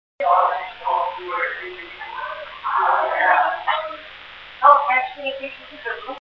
Audio recordings revealed some possible EVPs (electronic voice phenomena) and unusual noises which are included on a CDROM.
A small voice saying "hello" can be heard, also a short "dog bark".